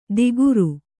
♪ diguru